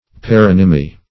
Search Result for " paronymy" : The Collaborative International Dictionary of English v.0.48: Paronymy \Pa*ron"y*my\, n. The quality of being paronymous; also, the use of paronymous words.